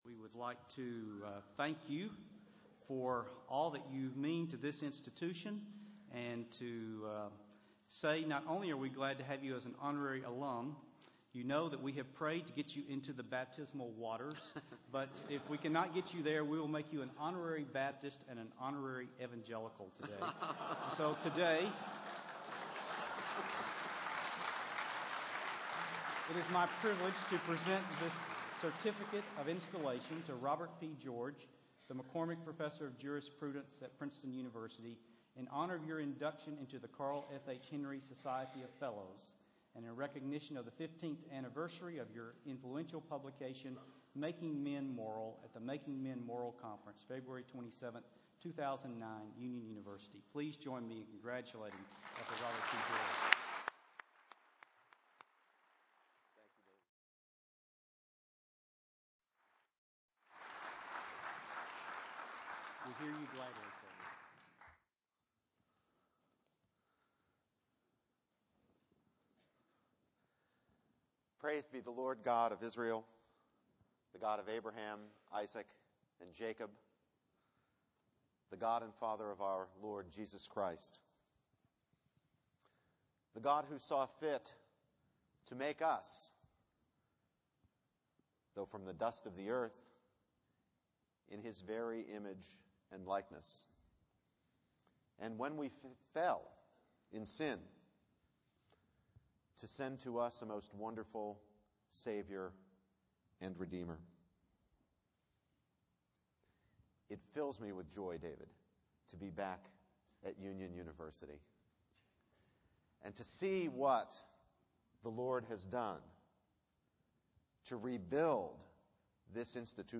Making Men Moral Chapel